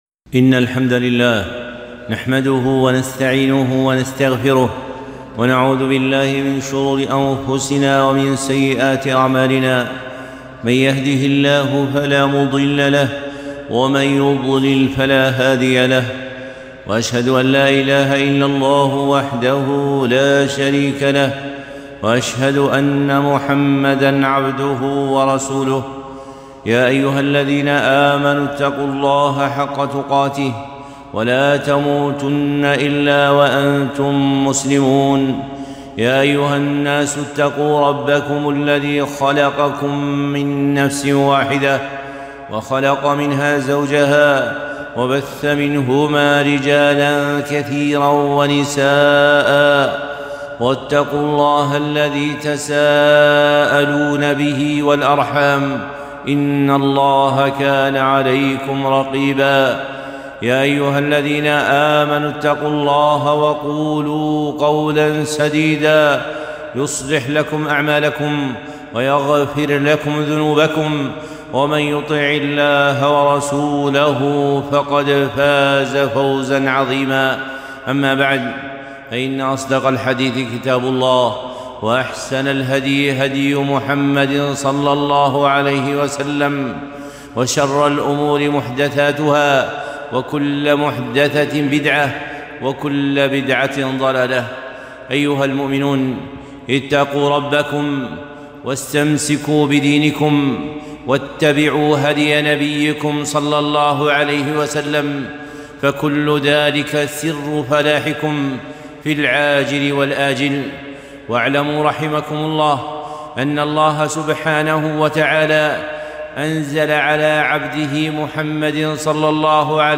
خطبة - التذكير بخلق الإنسان خطبة الجمعة ٧ رجب ١٤٤٢